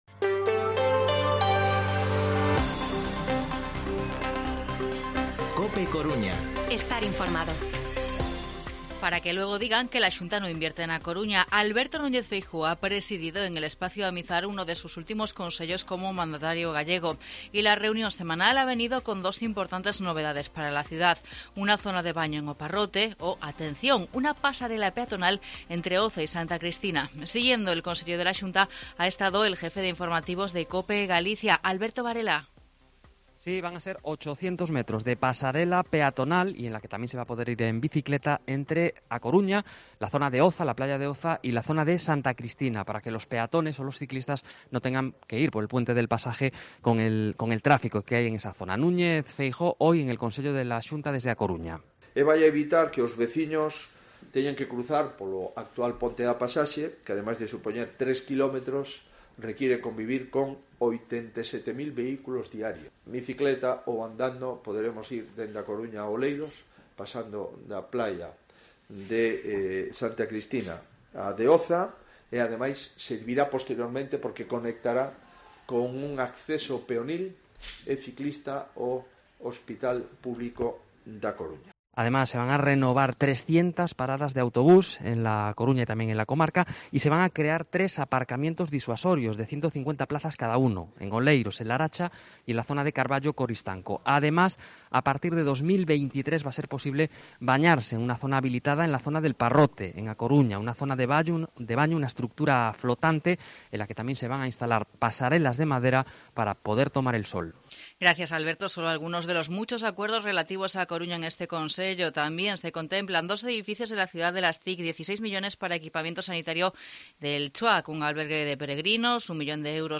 Informativo Mediodía COPE Coruña viernes, 8 de mayo de 2022 14:20-14:30